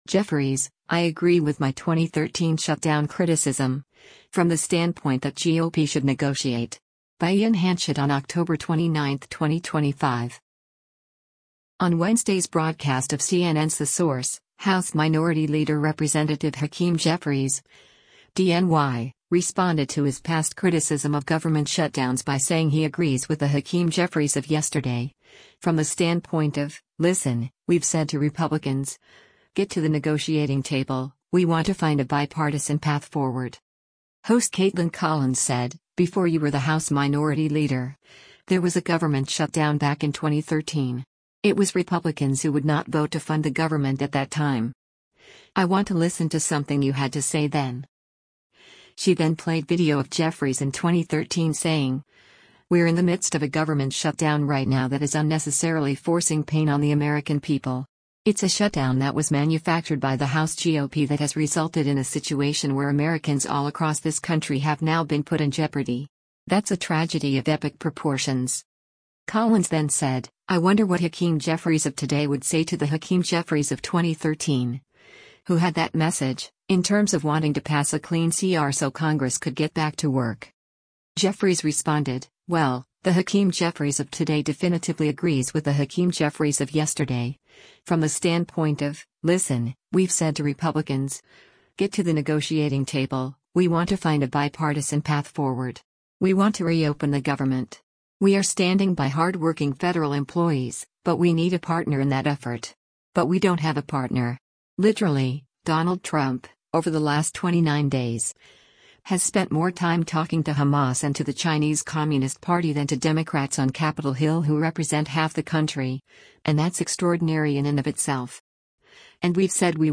On Wednesday’s broadcast of CNN’s “The Source,” House Minority Leader Rep. Hakeem Jeffries (D-NY) responded to his past criticism of government shutdowns by saying he “agrees with the Hakeem Jeffries of yesterday, from the standpoint of, listen, we’ve said to Republicans, get to the negotiating table, we want to find a bipartisan path forward.”